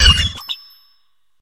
Cri de Frigodo dans Pokémon HOME.